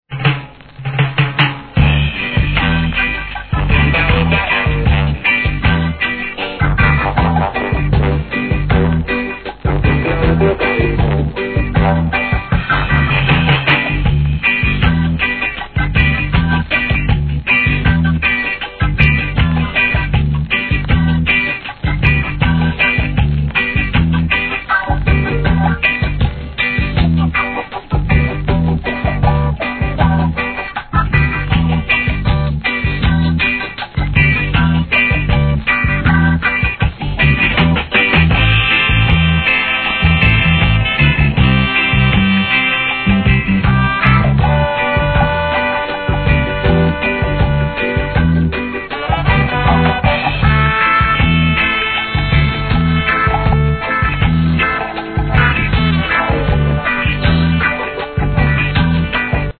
REGGAE
1975年、ごん太ベースのインスト物!!